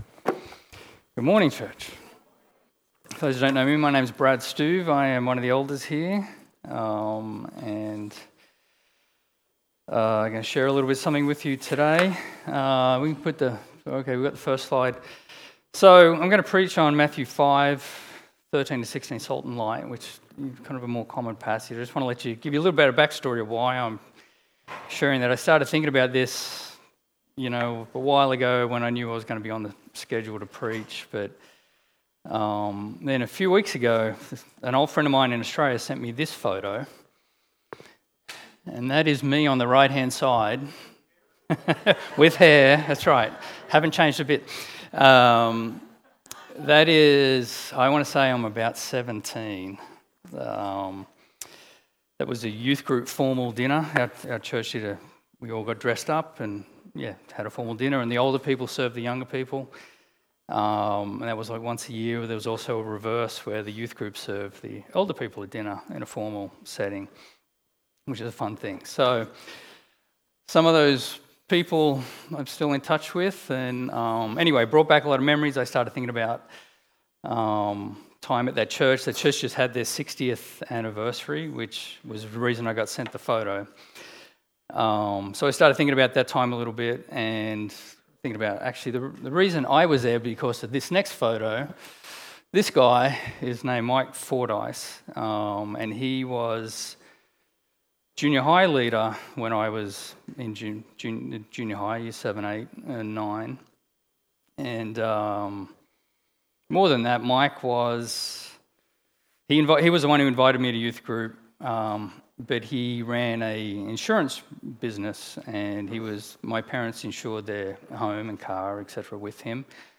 Matthew 5:13-16 Service Type: Sunday This Sunday our elder